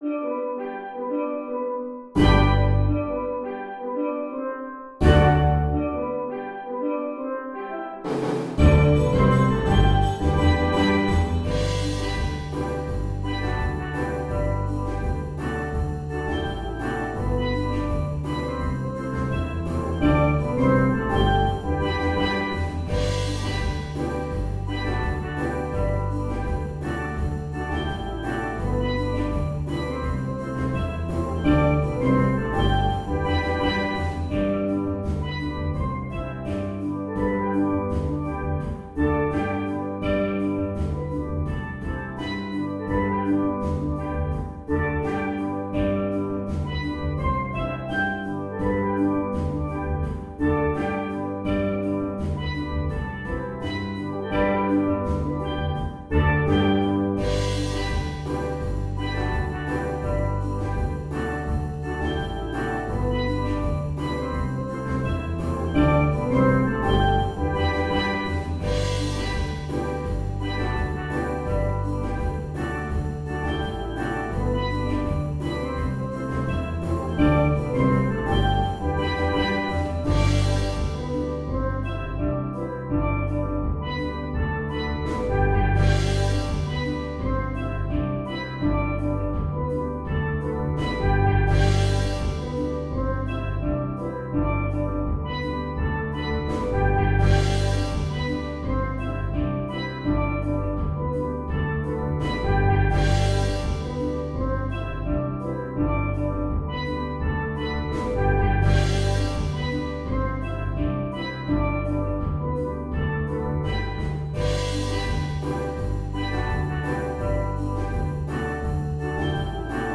Instrumentation: steel drum
pop, rock, contemporary, metal, instructional